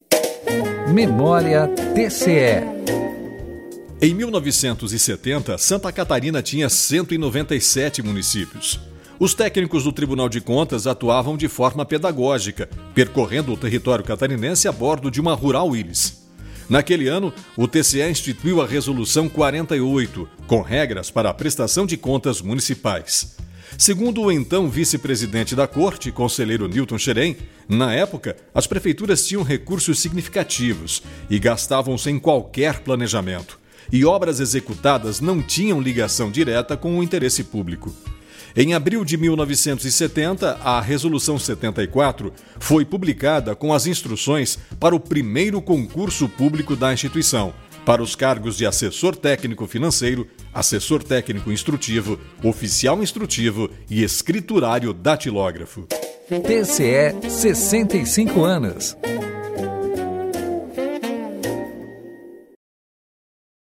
Este jornal eletrônico é uma realização da SL Comunicações, sob a supervisão da Assessoria de Comunicação Social do TCE.